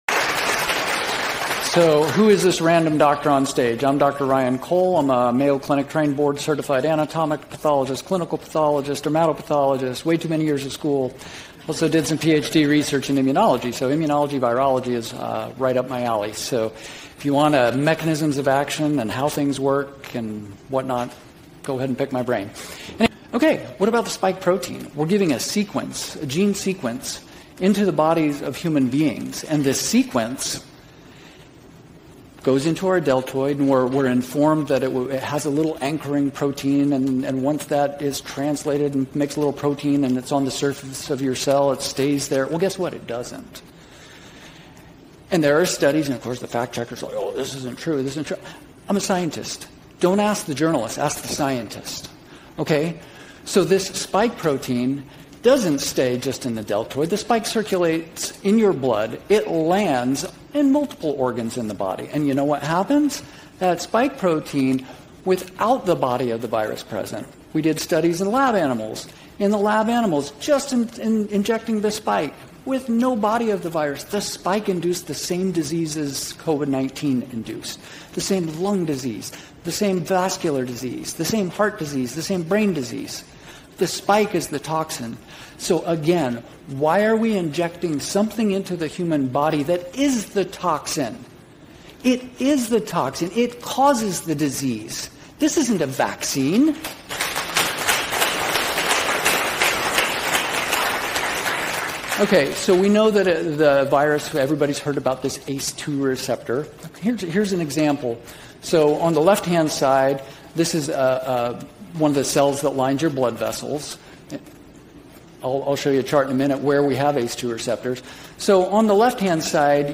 Pathologist gives presentation to peers of the science on the impact that the COVID vaccine has on the human body via autopsie results.